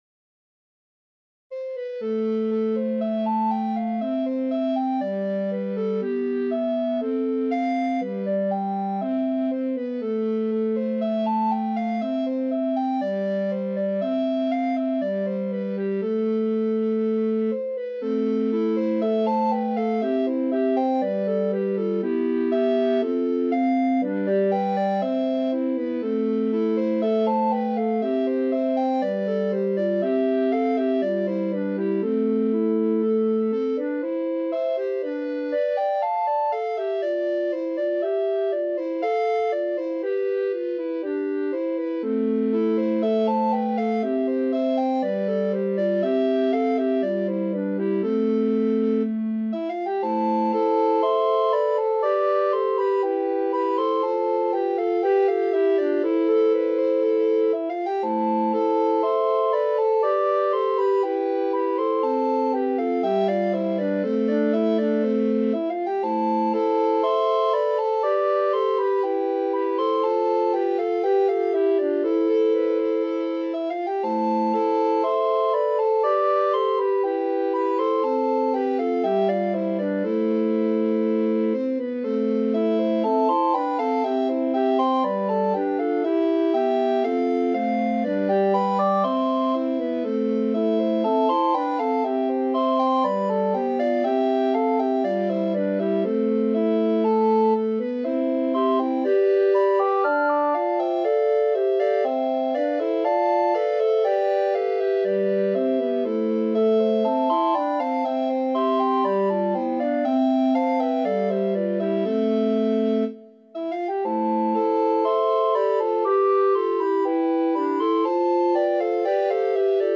St. Francis and the Birds (recorder quartet) (computer
This had required me to change the original key to better fit the range of an SATB group of recorders.
Once again I'm letting my computer recorder players "perform" the piece. They certainly get all of the pitches just right and their tempo never wavers. I'm sure if I spent several hours tinkering I could coax the computer to sound a little more human but I think it sounds OK for my purpose here.
StFrancisBirds2018Quartet.mp3